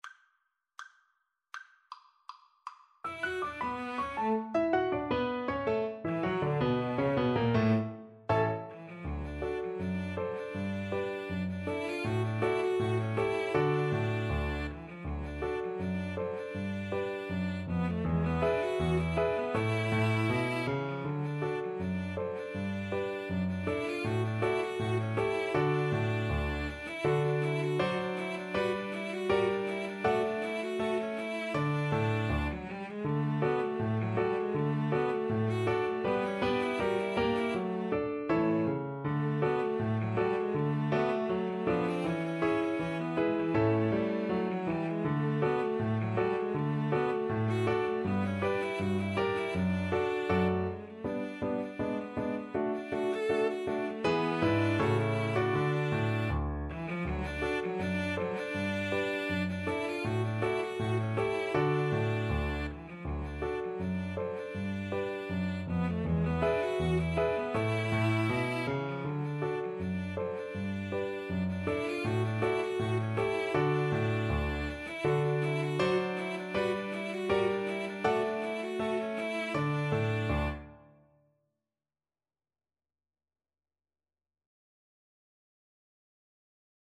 Cello 1Cello 2Piano
D major (Sounding Pitch) (View more D major Music for Cello Duet )
Presto (View more music marked Presto)
Jazz (View more Jazz Cello Duet Music)